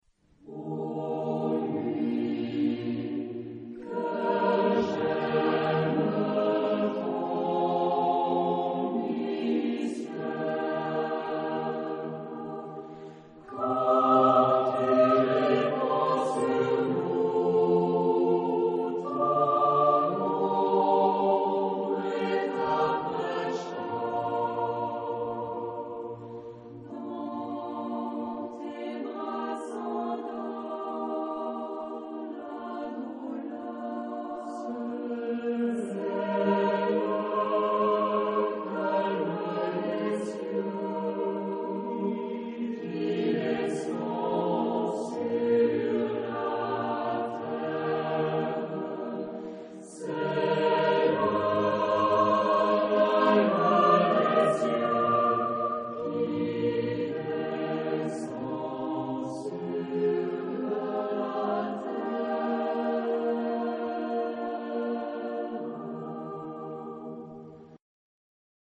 Genre-Style-Forme : Chœur ; Profane
Type de choeur : SATB  (4 voix mixtes )
Tonalité : mi bémol majeur